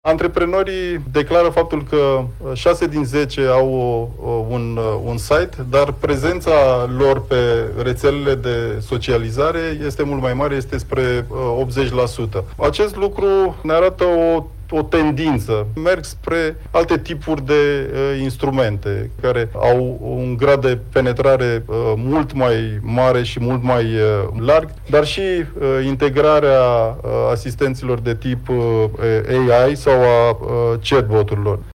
la prezentarea IMM Digital Index – care monitorizează prezența online a companiilor: